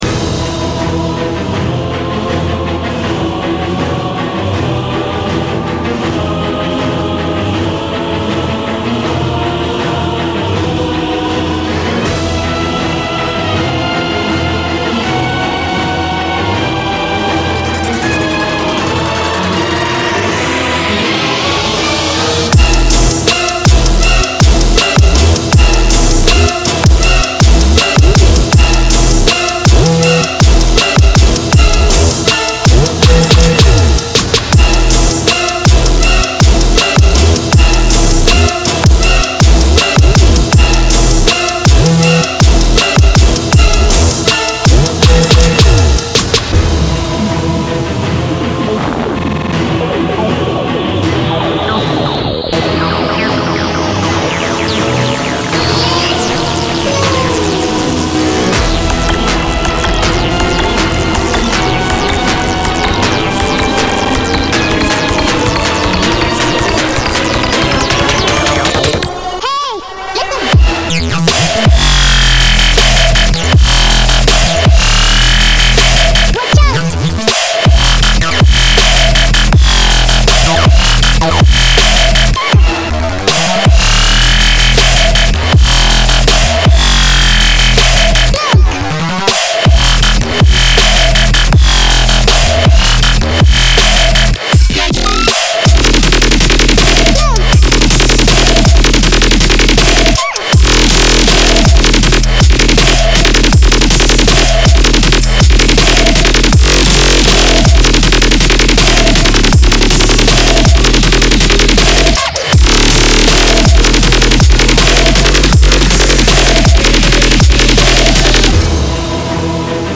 extremely loud, extremely aggressive, and very EDM remix
It's loud.